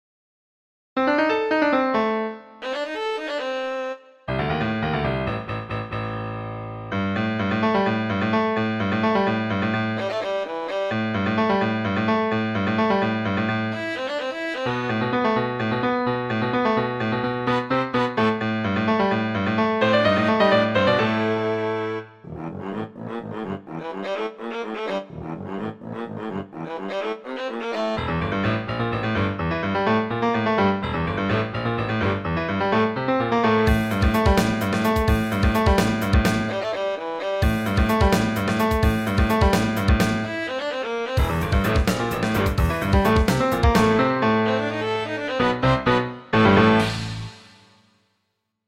BLUES-ROCK MUSIC